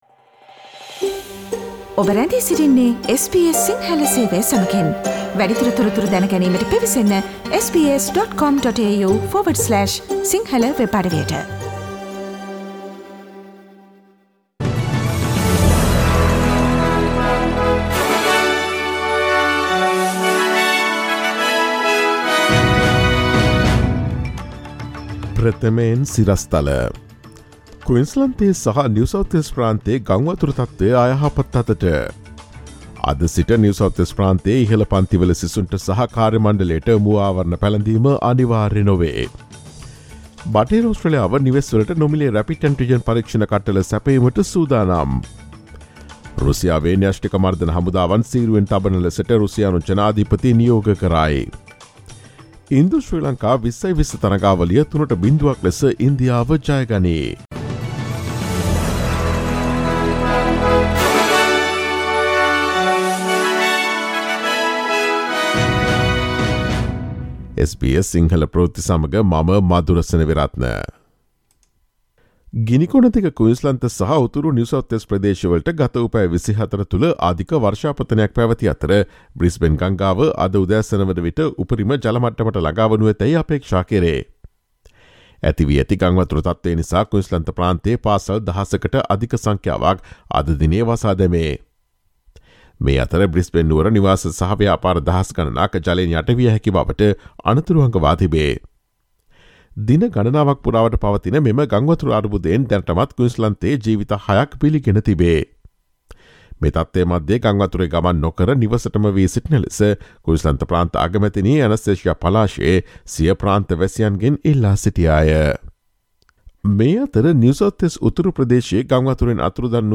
ඔස්ට්‍රේලියාවේ නවතම පුවත් මෙන්ම විදෙස් පුවත් සහ ක්‍රීඩා පුවත් රැගත් SBS සිංහල සේවයේ 2022 පෙබරවාරි 28 වන දා සඳුදා වැඩසටහනේ ප්‍රවෘත්ති ප්‍රකාශයට සවන් දීමට ඉහත ඡායාරූපය මත ඇති speaker සලකුණ මත click කරන්න.